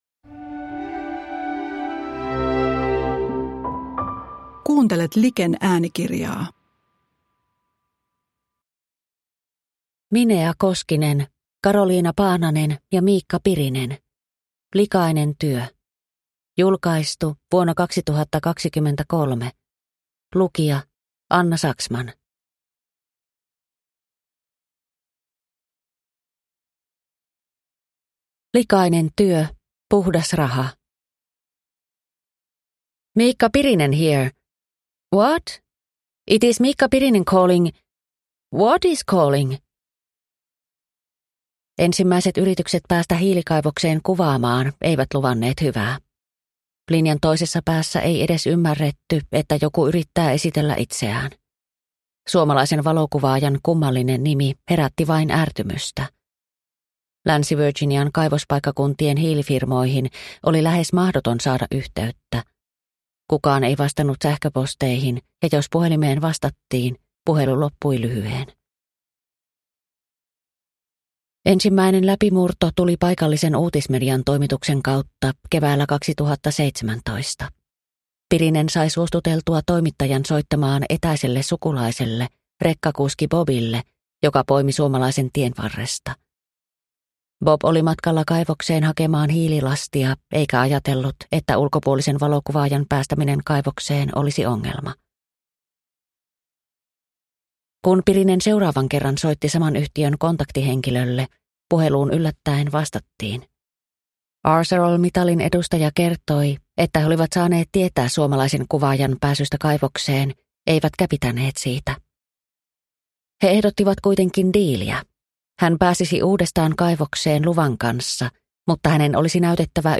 Likainen työ – Ljudbok – Laddas ner